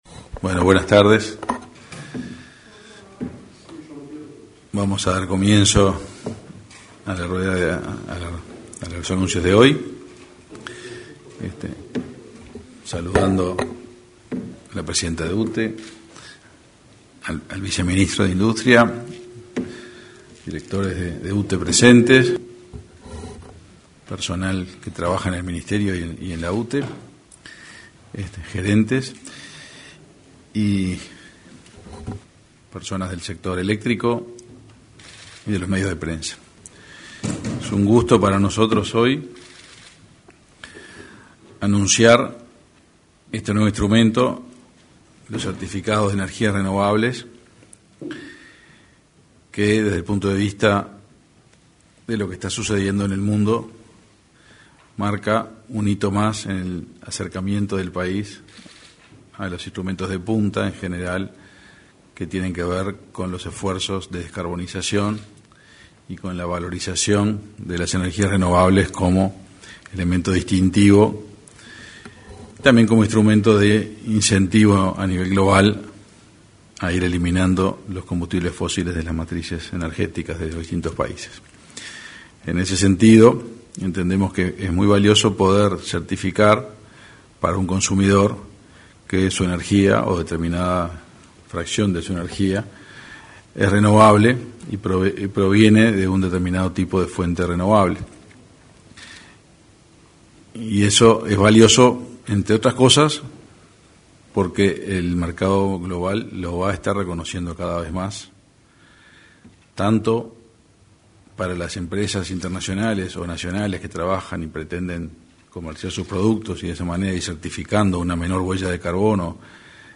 Lanzamiento de certificados de energías renovables 27/09/2021 Compartir Facebook X Copiar enlace WhatsApp LinkedIn Este lunes 27, el ministro de Industria, Energía y Minería, Omar Paganini, junto a la presidenta de UT.E, Silvia Emaldi, presentaron los certificados de energías renovables, en el salón de actos de Torre Ejecutiva.